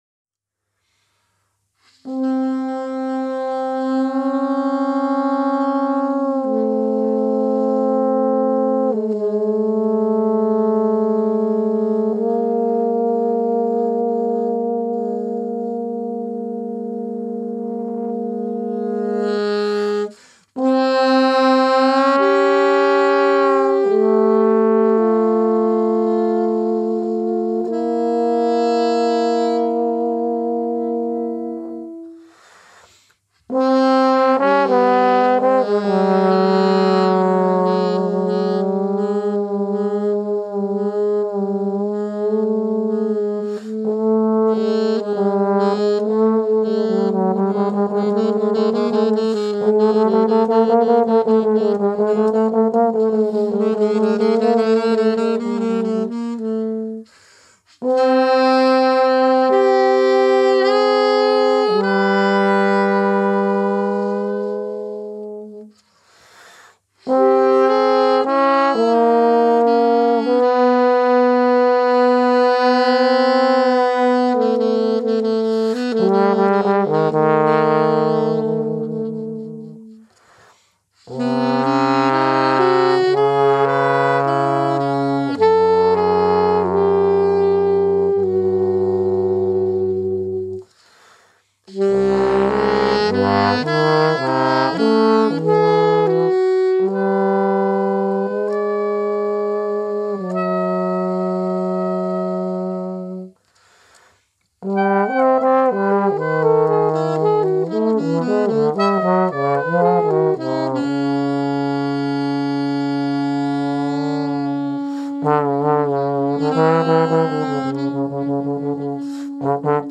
Aufgenommen am 29.01.2025 im Atelier